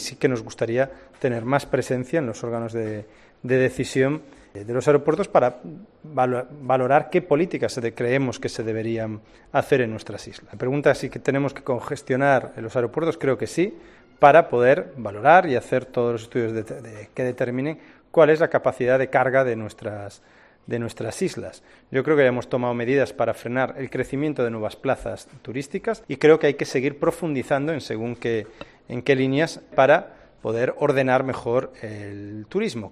Corte de voz de Negueruela
En la rueda de prensa tras el Consell de Govern, el portavoz del ejecutivo y conseller de Turismo ha eludido responder de manera directa sobre la petición pública de MÉS per Mallorca de que se limiten los vuelos en el Aeropuerto de Palma de Mallorca para reducir la "desmesurada llega de turistas que está sufriendo la isla".